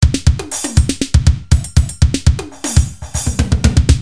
[影视音效][激情四射的Dj音效][剪辑素材][音频素材下载]-8M资料网